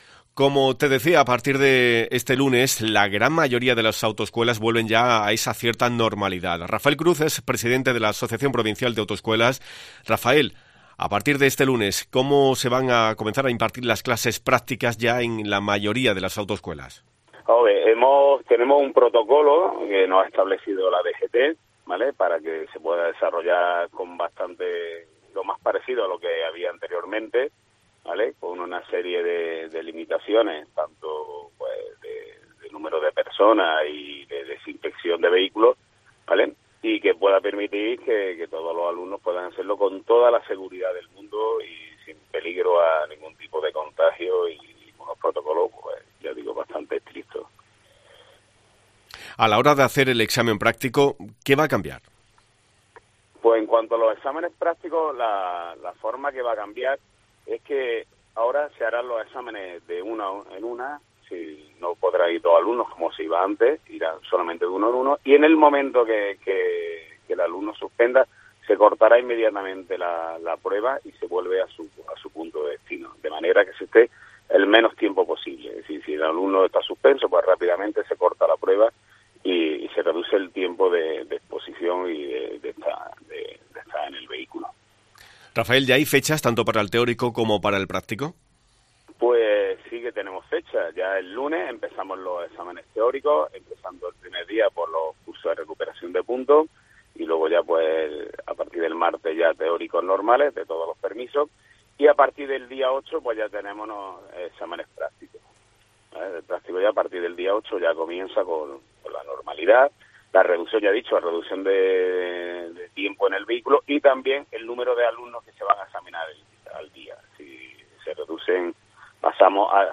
responde a las preguntas